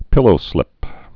(pĭlō-slĭp)